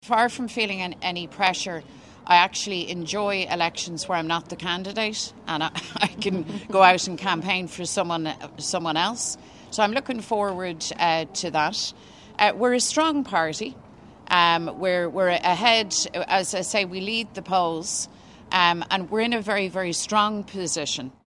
Sinn Féin members have gathered in Belfast for day two of the party’s Ard Fheis.
Leader Mary Lou McDonald doesn’t believe her position is under threat if the two upcoming by-elections don’t go Sinn Féin’s way: